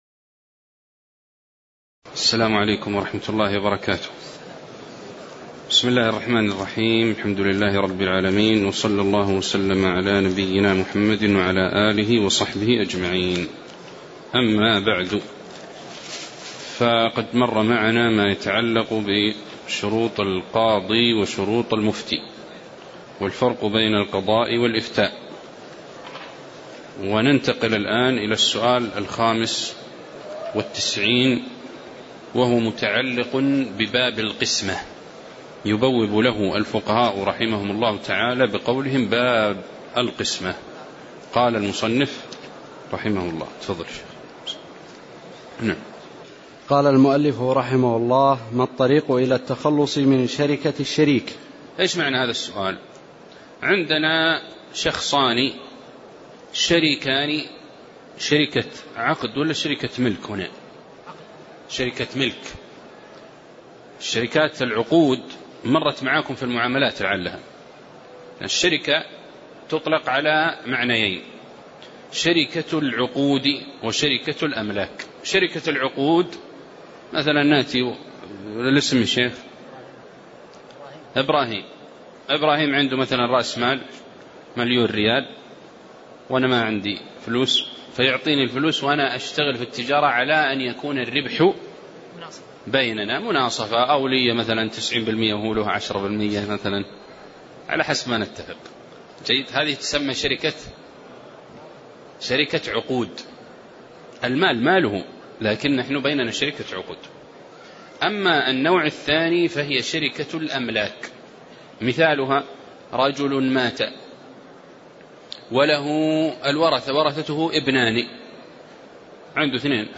تاريخ النشر ٢٣ شوال ١٤٣٨ هـ المكان: المسجد النبوي الشيخ